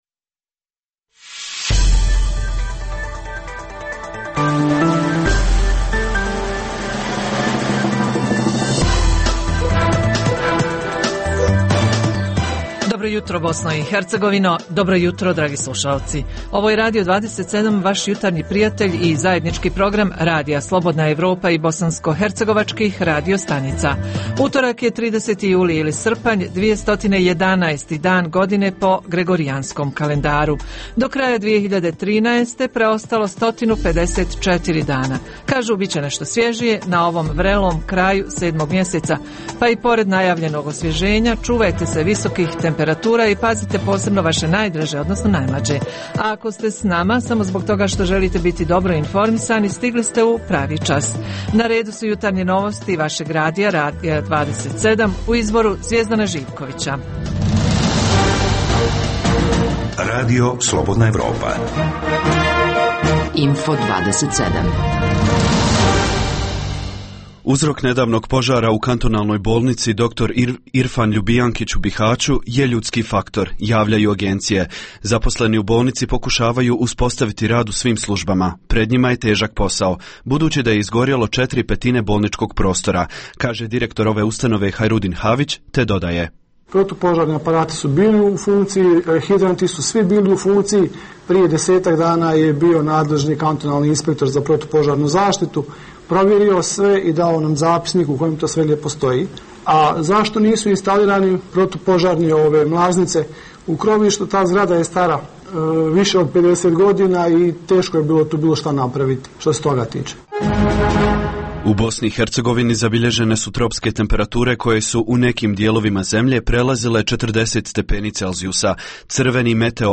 U jutarnjem programu, možete čuti: - Reporteri govore o tome kako popraviti kućni budžet u ovim ljetnim mjesecima. Jedan od načina je uzgoj ljekovitog bilja,kao što to rade žene nekih sela u opštinama Tešanj i Jablanica. Iz Prijedora i Brčko distrikta takođe stižu primjeri uspješnog prikupljanja ljekovitog bilja, gljiva, šumskih plodova, voća i povrća - Redovna rubrika je „Svijet interneta" - Uz tri pregleda najvažnijih vijesti, slušaoci mogu uživati u ugodnoj muzici.